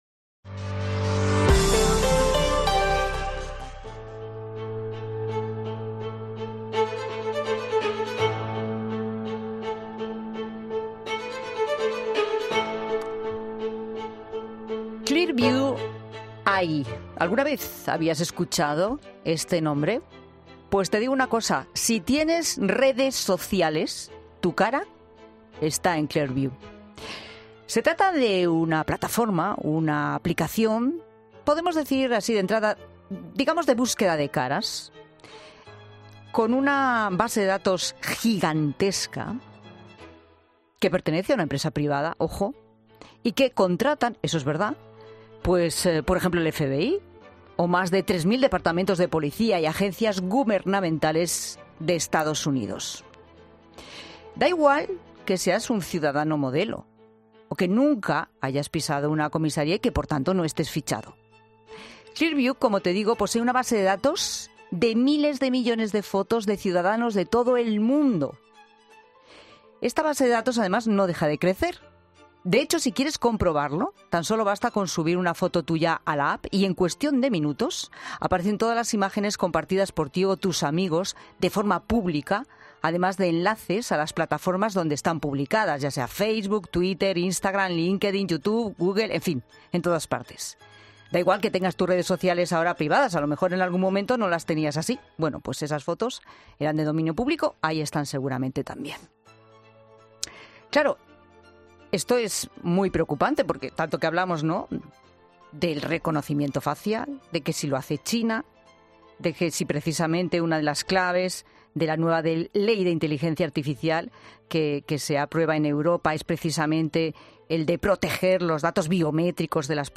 Hemos hablado en 'La Tarde'